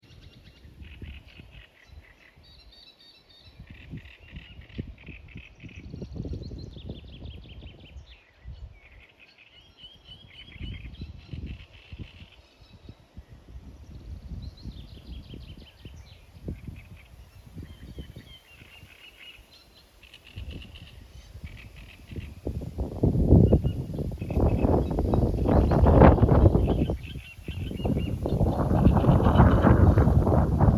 дроздовидная камышевка, Acrocephalus arundinaceus
Administratīvā teritorijaValkas novads
СтатусПоёт